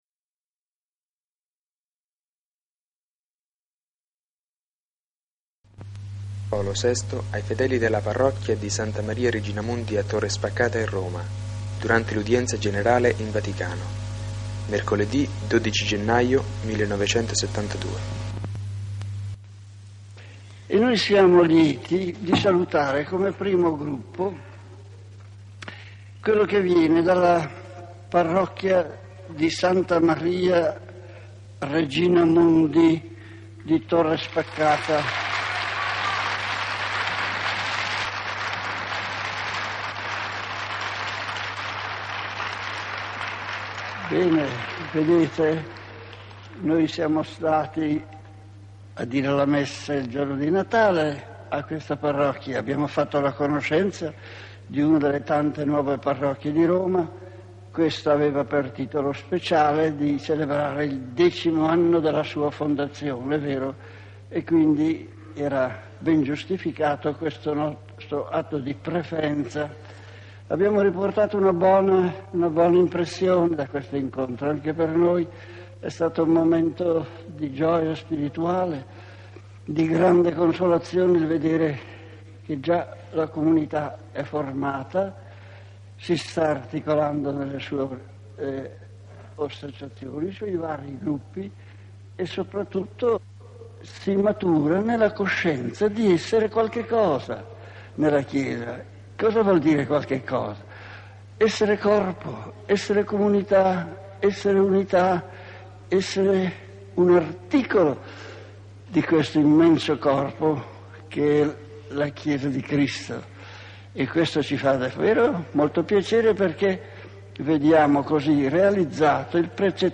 Altra 'chicca' dei nostri archivi è l'audio originale del saluto che il papa Paolo VI volle rivolgere ai parrocchiani, nell'udienza del 12 gennaio 1972, che erano andati a ringraziarlo della vista.